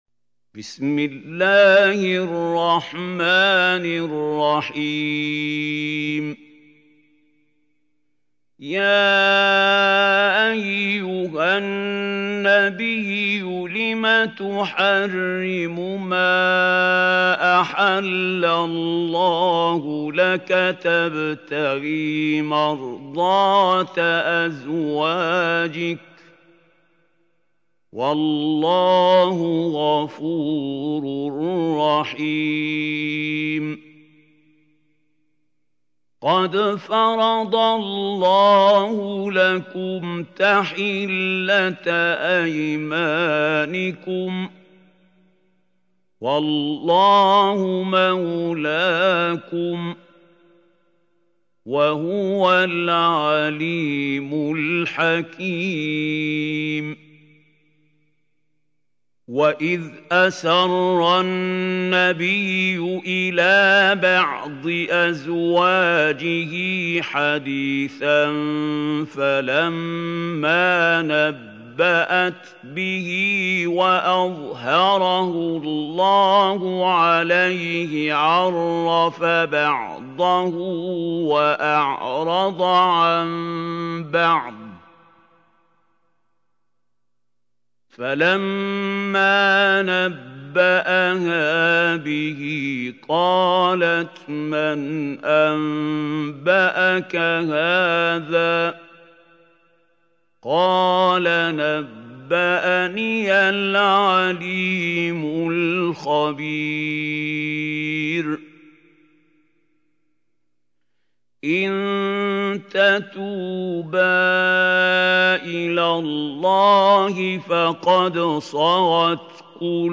سورة التحريم | القارئ محمود خليل الحصري